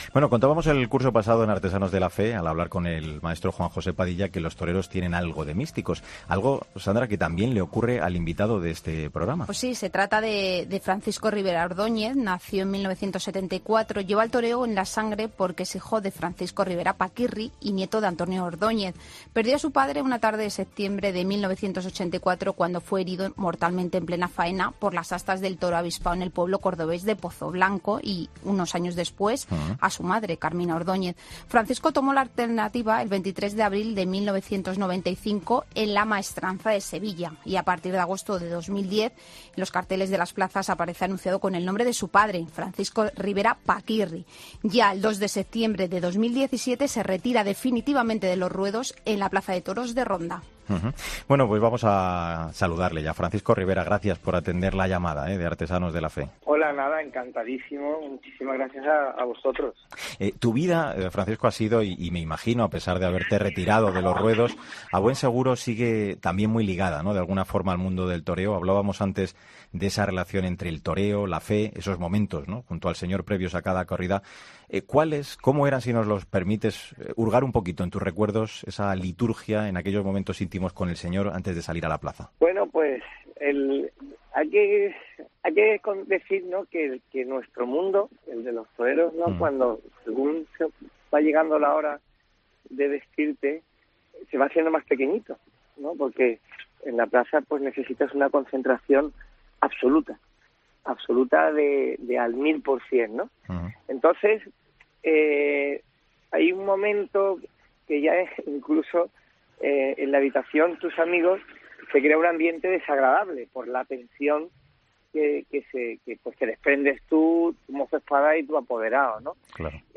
En la segunda entrega de esta segunda temporada de ‘Artesanos de la Fe’ nos acompaña el ex torero Francisco Rivera Ordóñez.